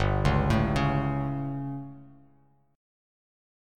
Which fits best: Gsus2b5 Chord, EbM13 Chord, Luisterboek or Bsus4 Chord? Gsus2b5 Chord